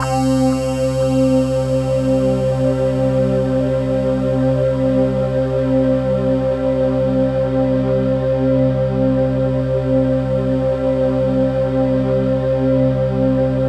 CHIMEPADC3-L.wav